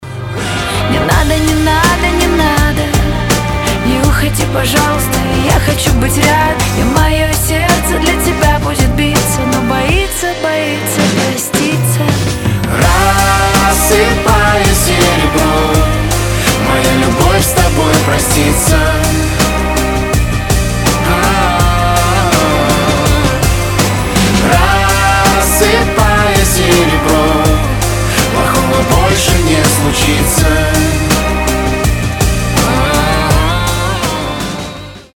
• Качество: 320, Stereo
поп
дуэт
романтичные
эмоциональные
трогательные